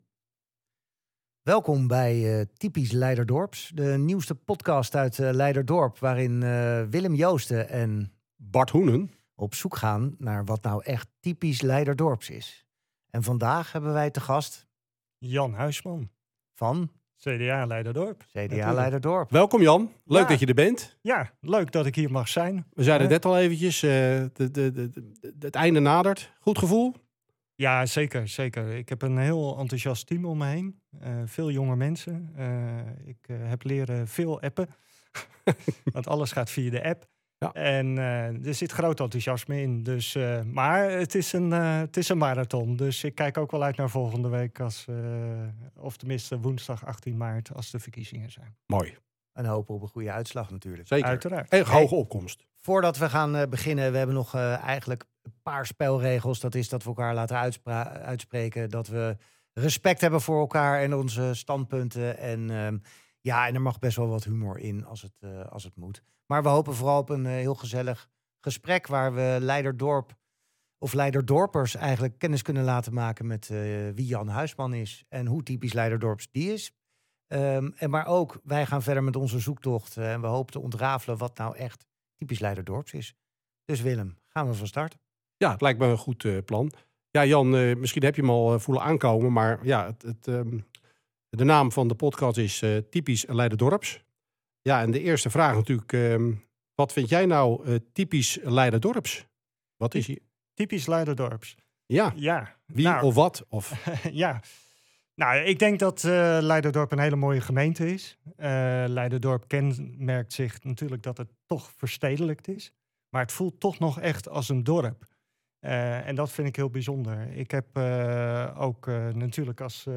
Typisch Leiderdorp is een podcast van twee opgewekte, ondernemende Leiderdorpers met hart voor het dorp.